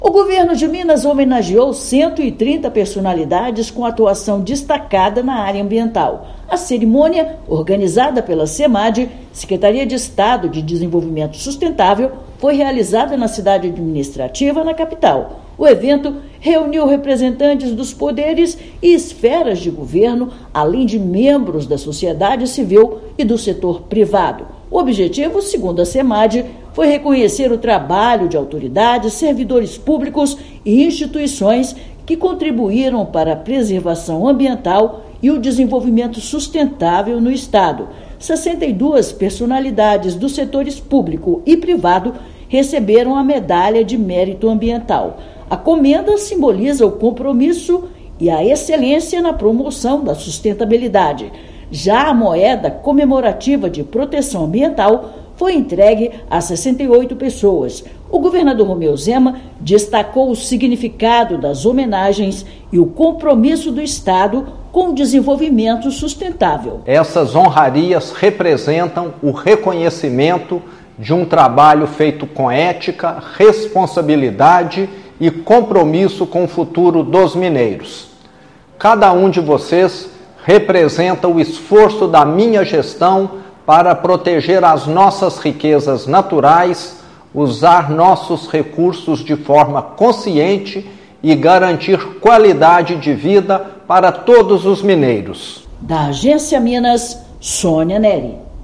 Solenidade do Mérito Ambiental de 2025 reconheceu autoridades, servidores e representantes da sociedade civil. Ouça matéria de rádio.